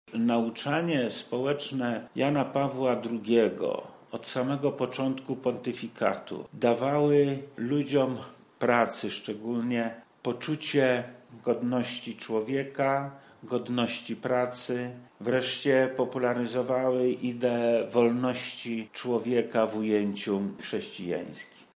Zapytaliśmy wojewodę lubelskiego Lecha Sprawkę, jak papież Polak przyczynił się do upadku komunizmu w naszym kraju: